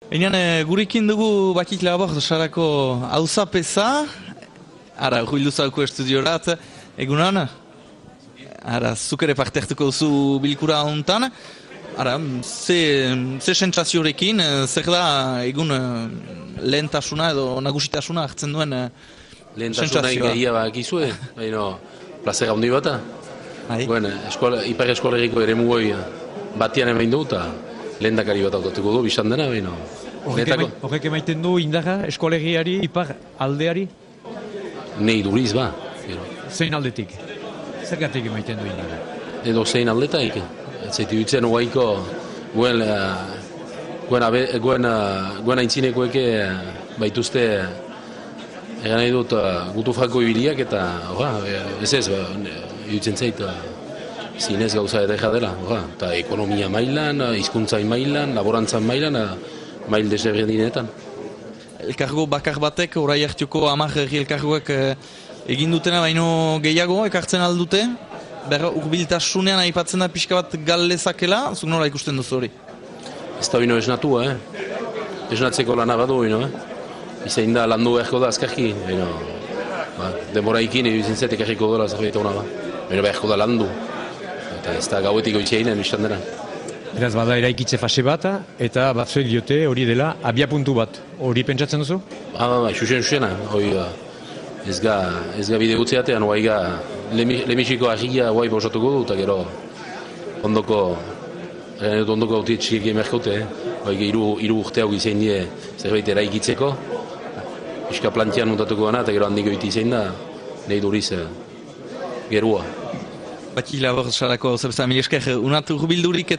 EUSKAL ELKARGOA – Euskal Irratien zuzeneko emankizun berezia
Bilkurara iristen ari ziren hainbat hautetsiren eta ordezkari politikoren hitzak ere bildu dituzte: